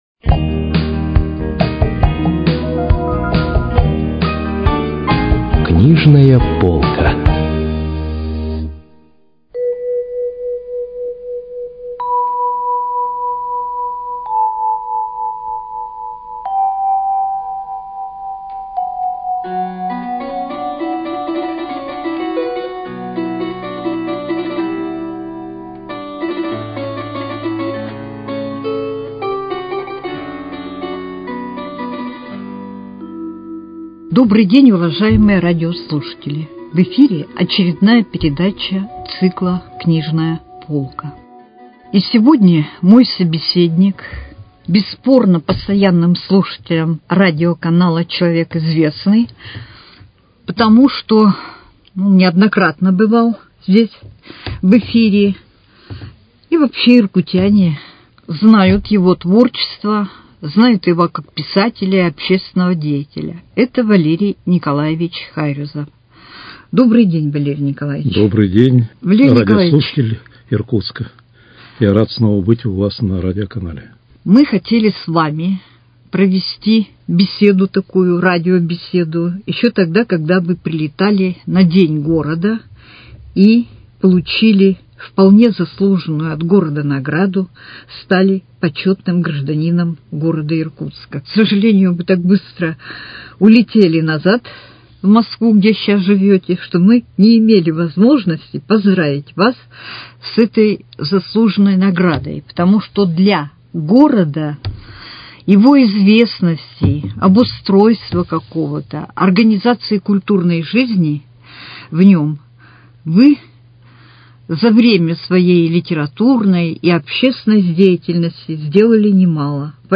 Книжная полка: Беседа с писателем Валерием Хайрюзовым